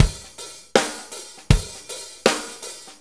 Shuffle Loop 23-04.wav